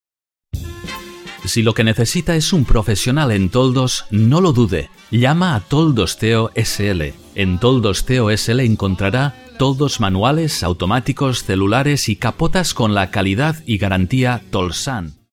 Experience in: Corporate Videos, E-learning, Documentaries, Training Videos, Webs, Audio Guides, TV, Radio, Games, Telephony, etc. Voice-overs done for: E-Bay, BBVA, RENFE, Iberdrola, Acciona, Vodafone, C.A.S.A. aeronáutica, Hyundai, Tele5 Atlas, SM Publicaciones, La Caixa, Mexicana Airlines, NH Hoteles, Volkswagen, Loctite, Rockwell, Mercadona, Caprabo, Consum, Gandía TV…etc Own recording studio. A Neumann U87 mic is used for all voice work.
Spanish (castilian) voice with over 20 years experience, can be a warm voice or authoratative.
Sprechprobe: Werbung (Muttersprache):